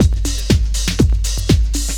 OSH Milky Beat 1_121.wav